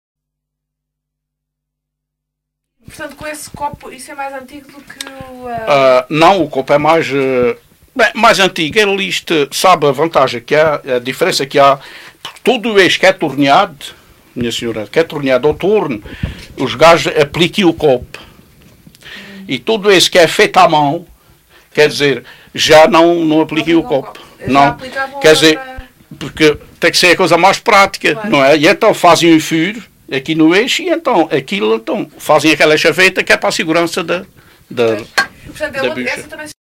LocalidadeCastelo de Vide (Castelo de Vide, Portalegre)